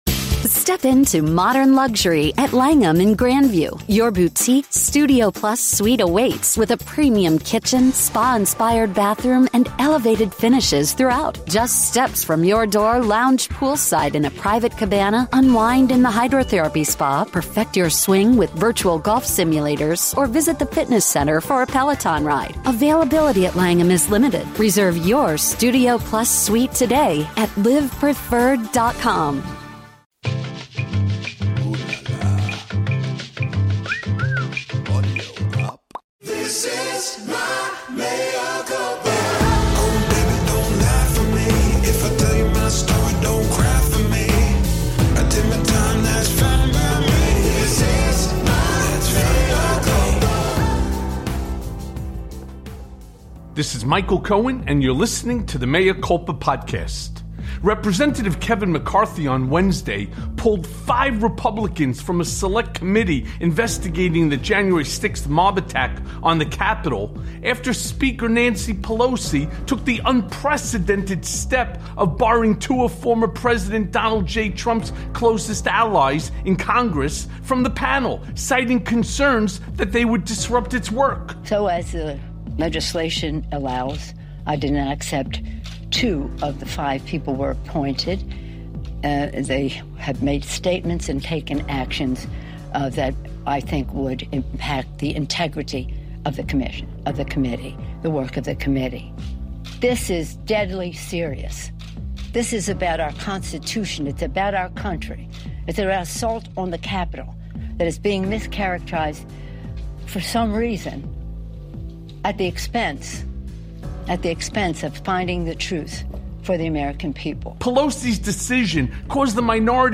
The GOP's Reckless Imbeciles Imperil Jan. 6th Commission + A Conversation with John Fugelsang